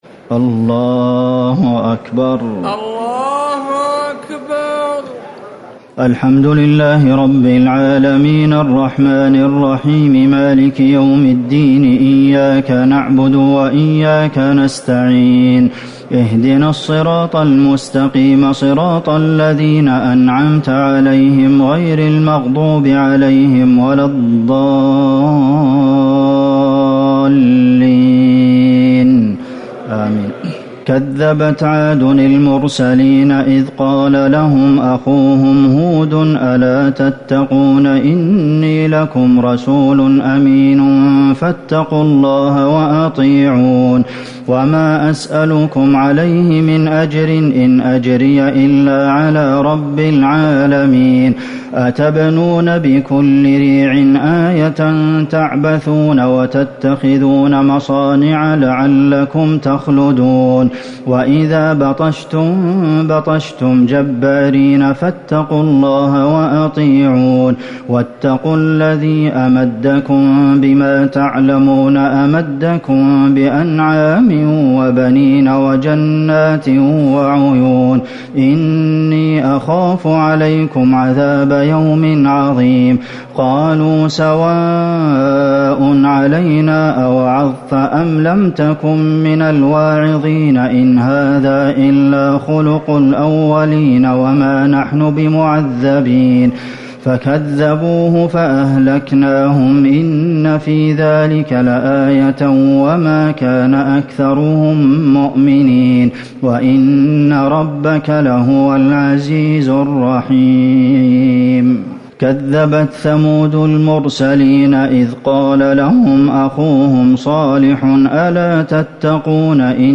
ليلة ١٨ رمضان ١٤٤٠ من سورة الشعراء ١٢٣ الى النمل ٥٣ > تراويح الحرم النبوي عام 1440 🕌 > التراويح - تلاوات الحرمين